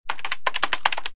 Keyboard6.wav